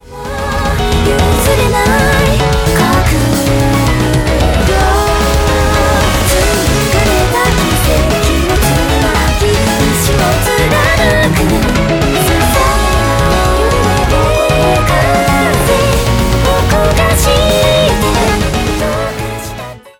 I made an extremely fast-paced rock song!Please enjoy it!!